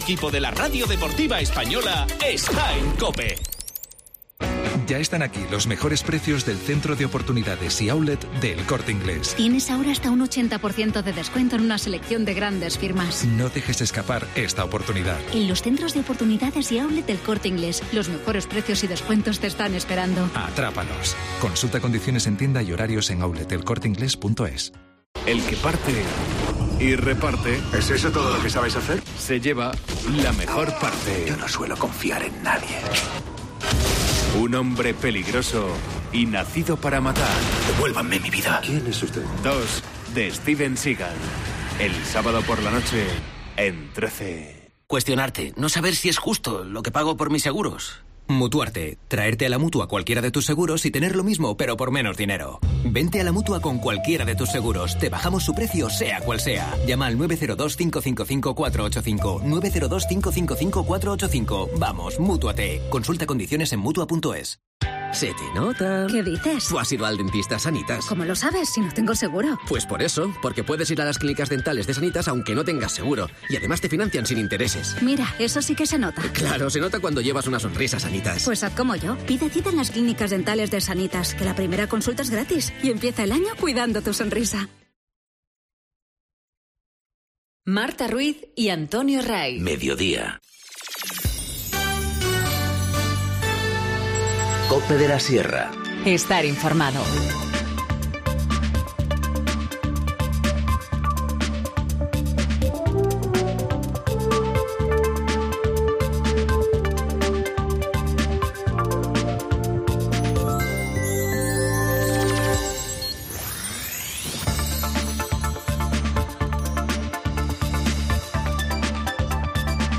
Informativo Mediodía 10 enero- 14:50h